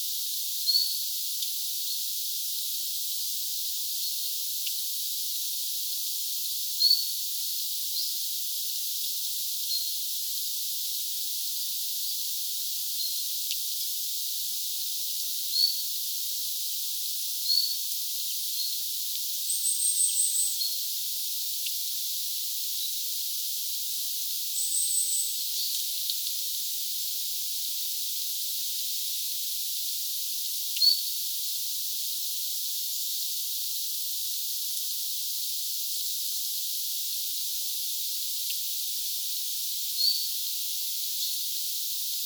jalleen_mahdollinen_idantiltaltti_aanimaaritetty_lahes_samassa_paikassa_kuin_viimeksi.mp3